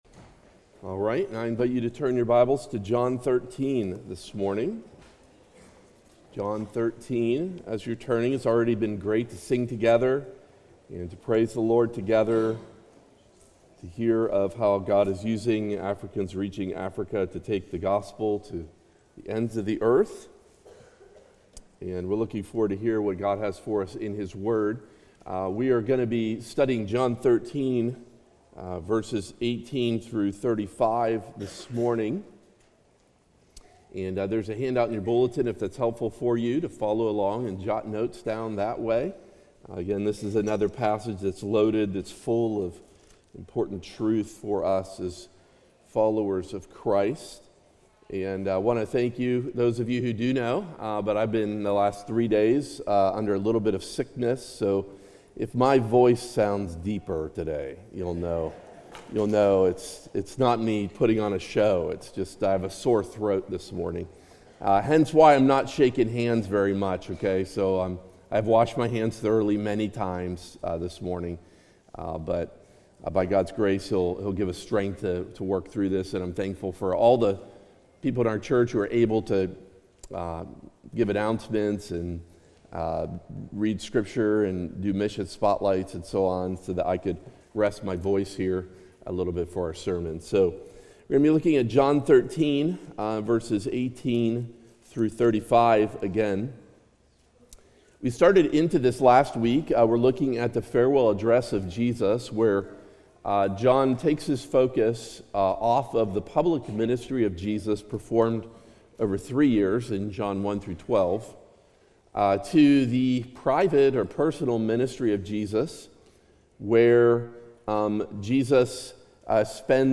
Colonial Baptist Church Sermons